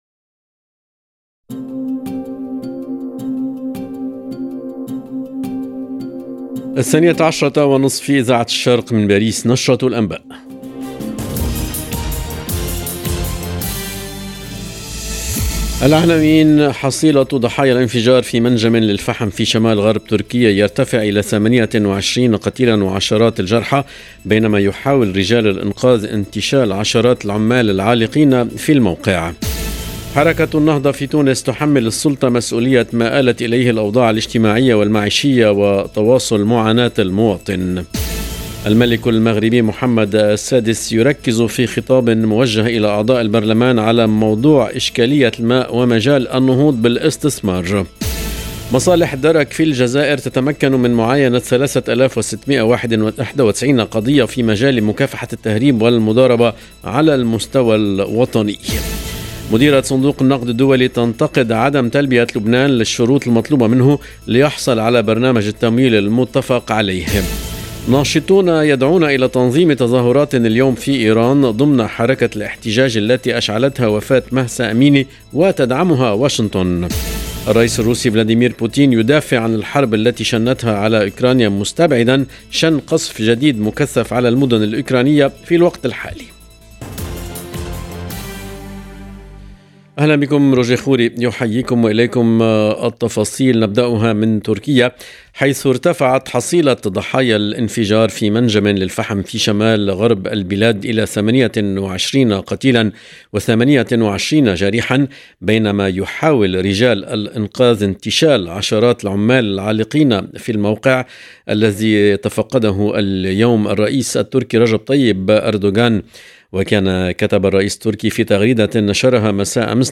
LE JOURNAL EN LANGUE ARABE DE MIDI 30 DU 15/10/22